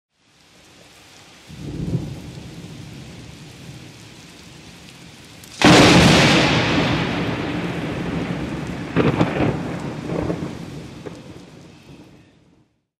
lightning-strike